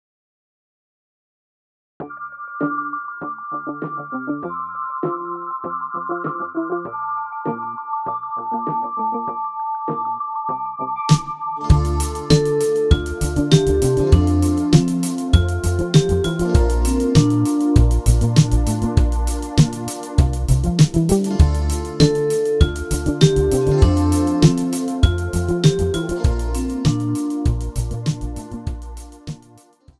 Instrumental Solos Cello